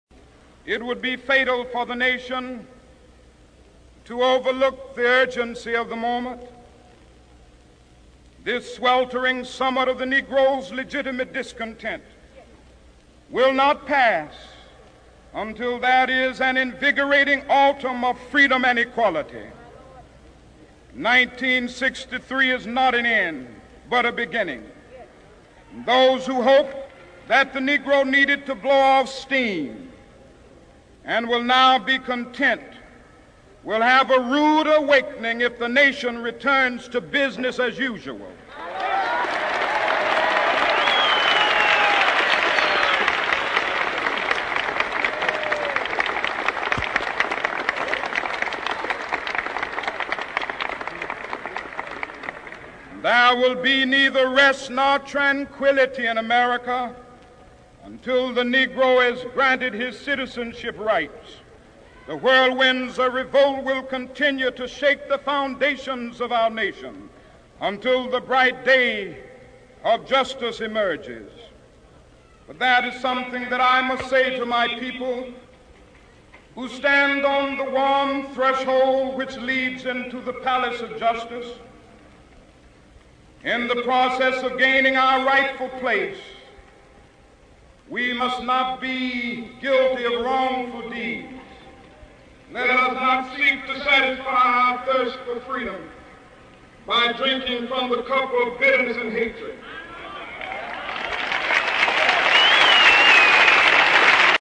名人励志英语演讲 第61期:我有一个梦想(4) 听力文件下载—在线英语听力室